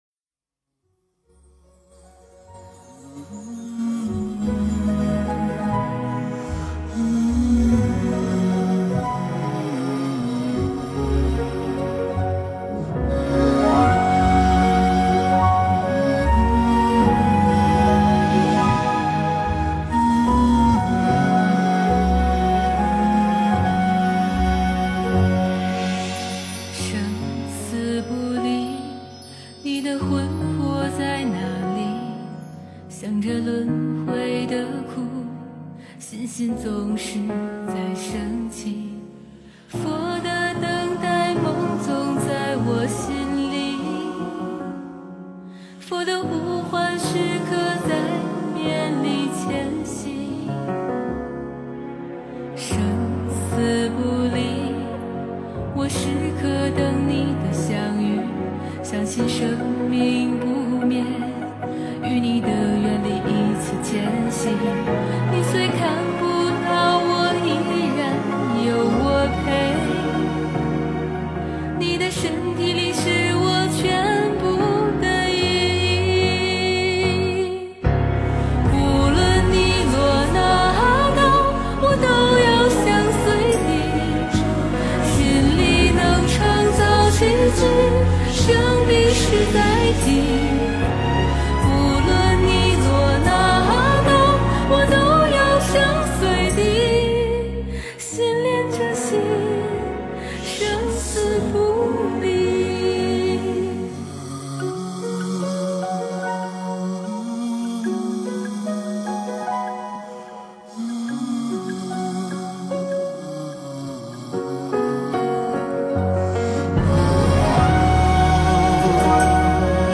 佛教音乐
佛音 诵经 佛教音乐 返回列表 上一篇： 舍利花 下一篇： 俗人 相关文章 古风小百合--空雨 古风小百合--空雨...